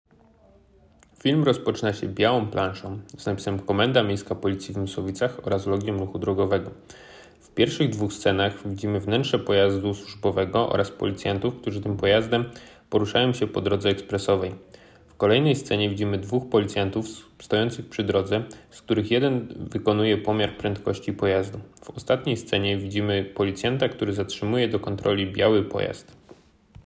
Opis nagrania: audiodeskrypcja do filmu